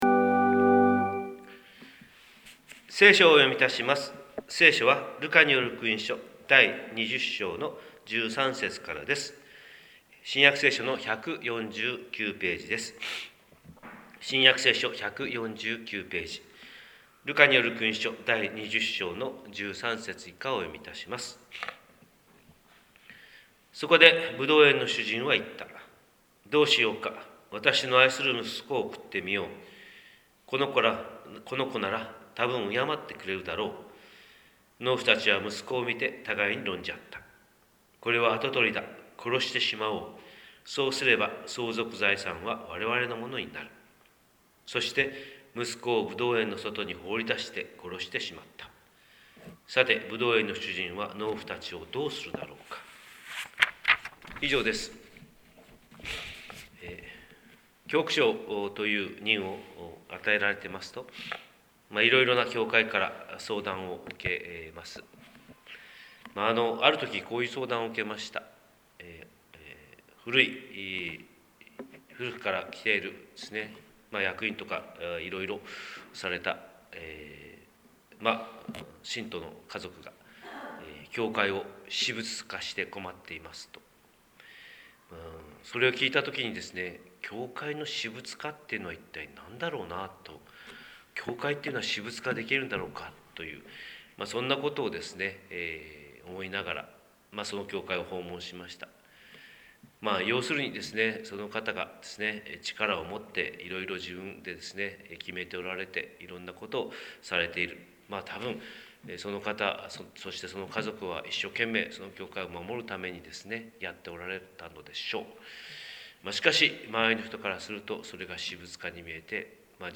神様の色鉛筆（音声説教）: 広島教会朝礼拝250307